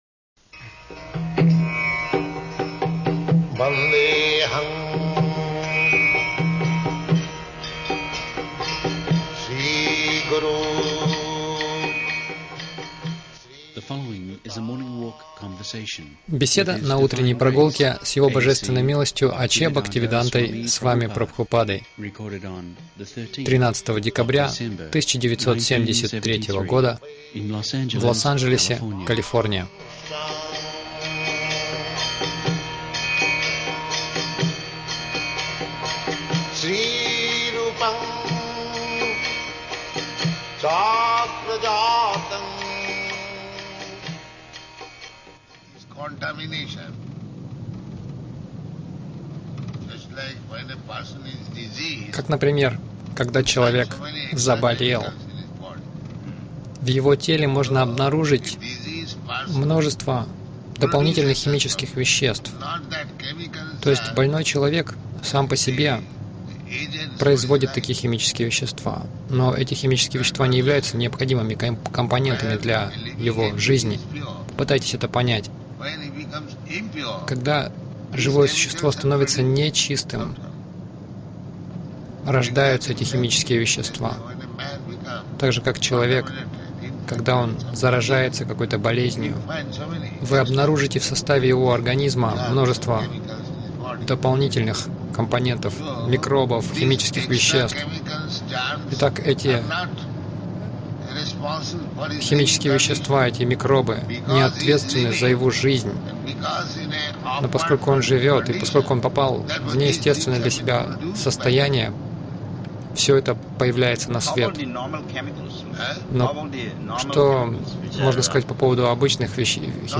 Милость Прабхупады Аудиолекции и книги 13.12.1973 Утренние Прогулки | Лос-Анджелес Утренние прогулки — Негодяи не знают, кто хозяин всего Загрузка...